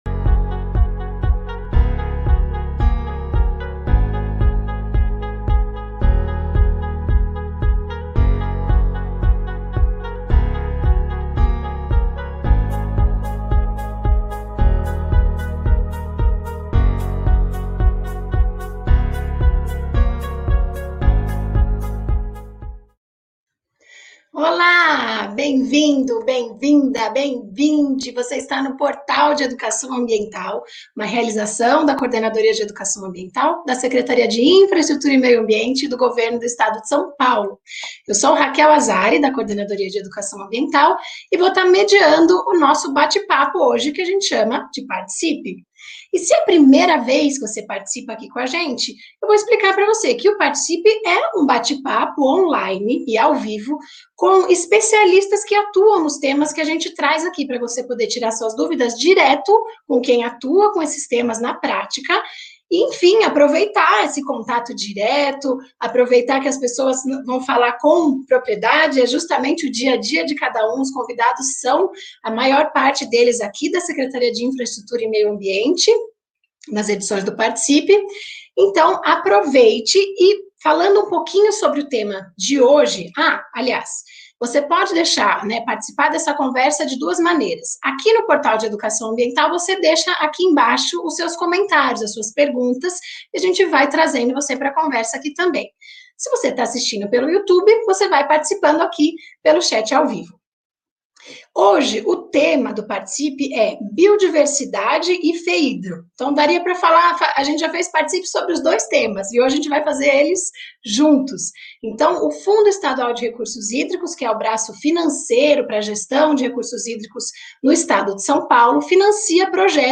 Aproveite esse contato direto com as analistas dos projetos!